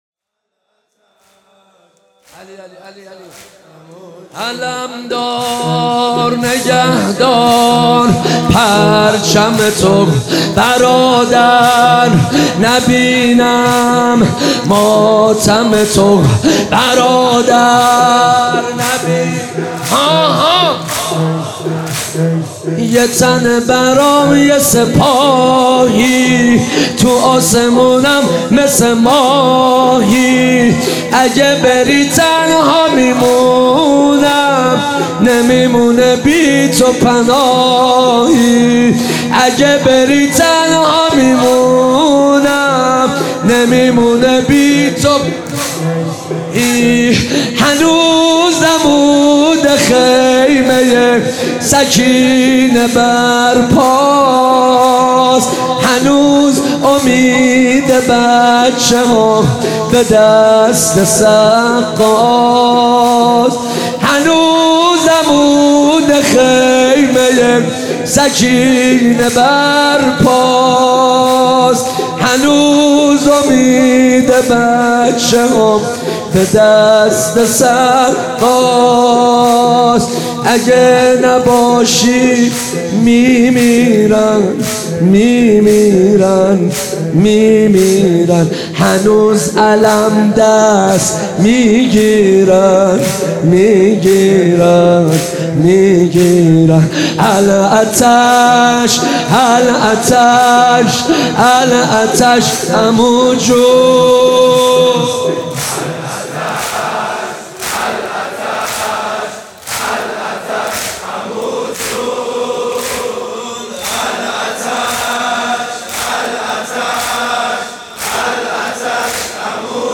مداح
مناسبت : شب هشتم محرم
قالب : شور